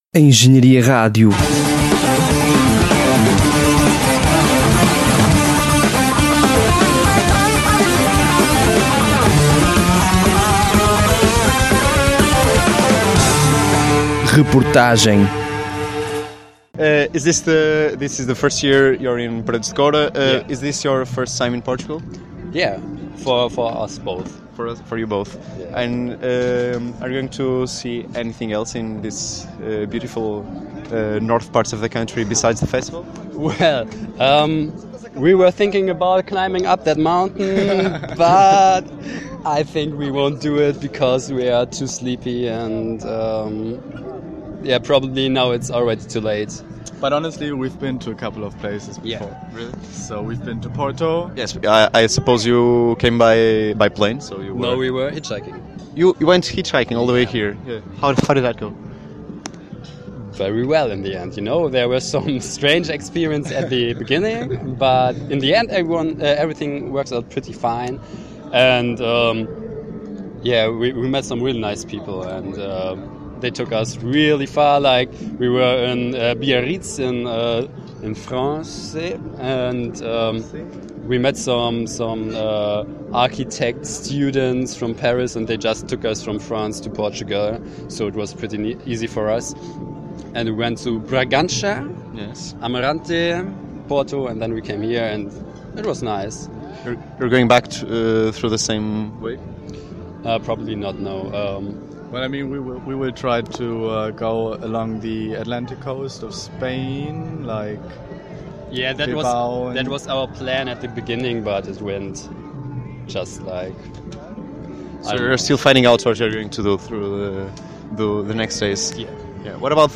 Concluído o primeiro dia do Vodafone Paredes de Coura, e enquanto nos preparamos para um dia já esgotado, a Engenharia Rádio faz chegar aos seus ouvintes um retrato sonoro do ambiente que se vive no Alto Minho por estes dias. E entre os entrevistados, verificamos que esta rádio encontra sempre ouvintes em todos os palcos!